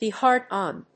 アクセントbe hárd on…